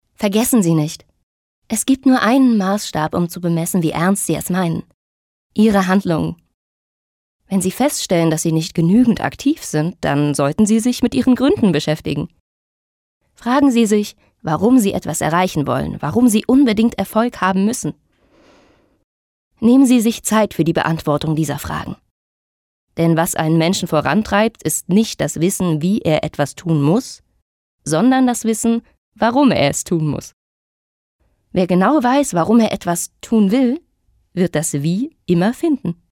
Sprechprobe: eLearning (Muttersprache):
Sachbuch.mp3